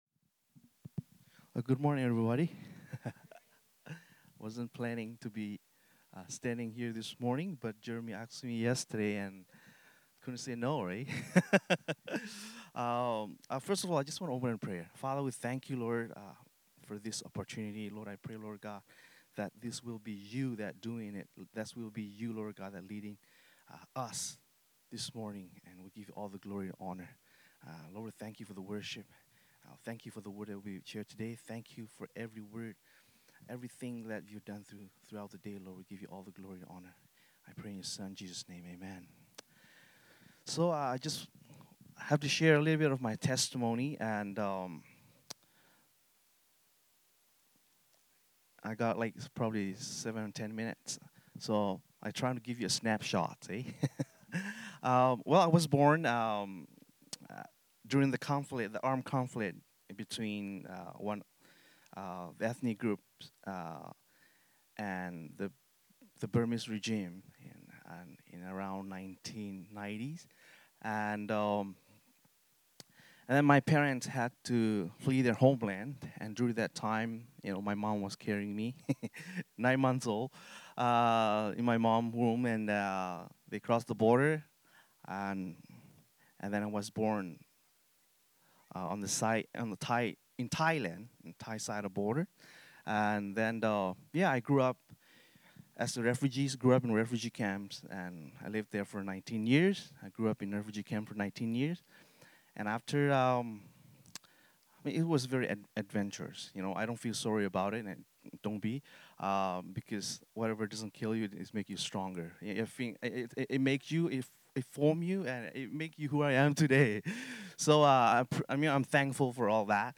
Sunday Messages | Transformation Church